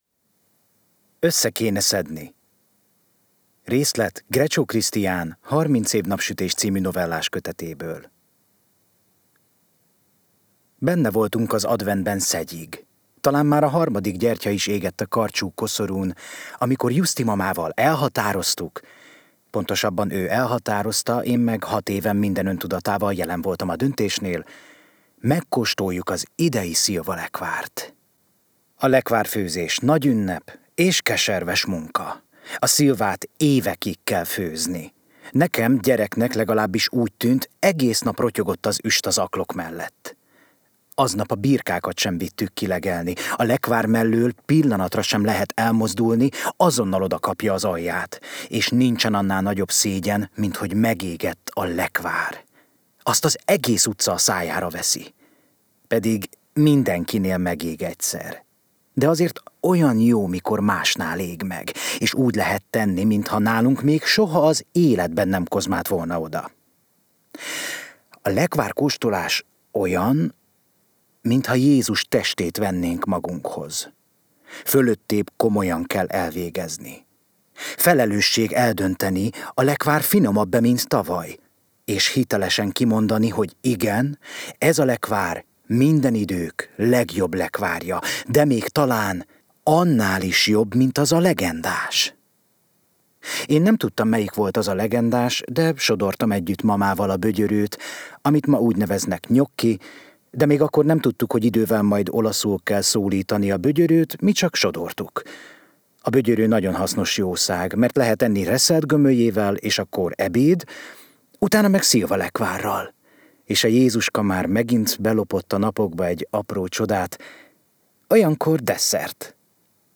Novella részlet
színművész előadásában